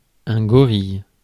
Ääntäminen
Synonyymit garde du corps goon armoire à glace Ääntäminen France: IPA: /ɡɔ.ʁij/ Haettu sana löytyi näillä lähdekielillä: ranska Käännös Substantiivit 1. горила {f} (goríla) Suku: m .